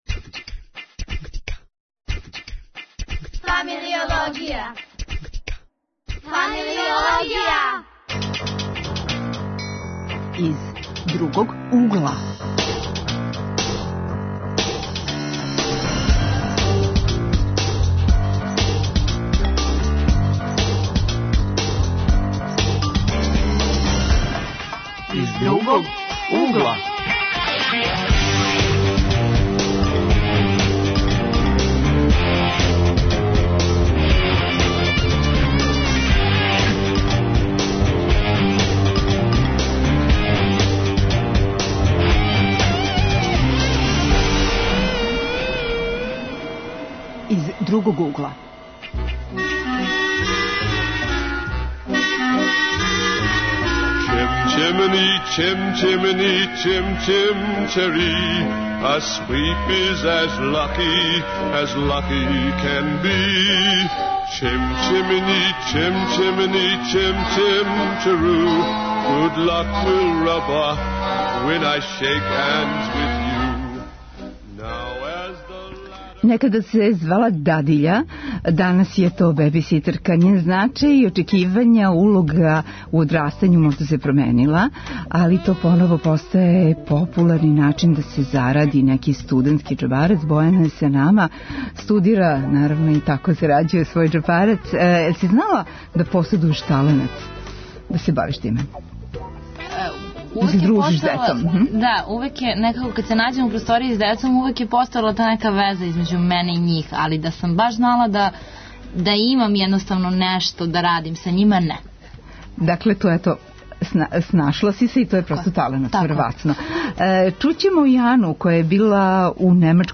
Гости: млади који код нас и у свету на тај начин зарађују џепарац.